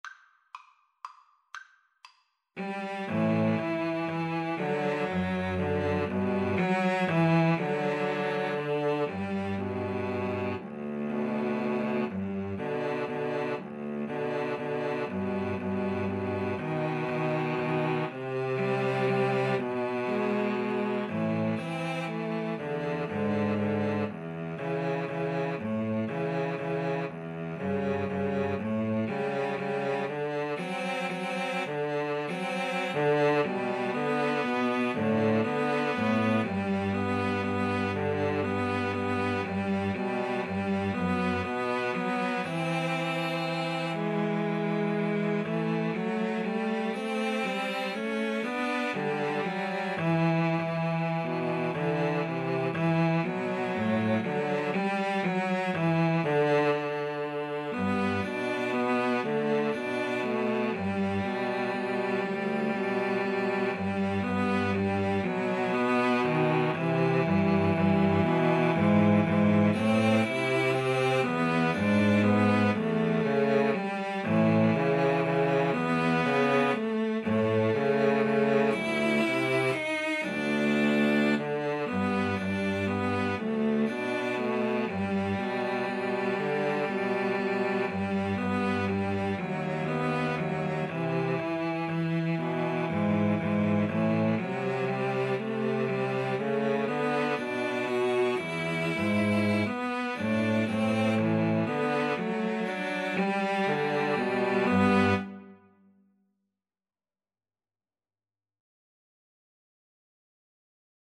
3/4 (View more 3/4 Music)
= 120 Tempo di Valse = c. 120
Cello Trio  (View more Intermediate Cello Trio Music)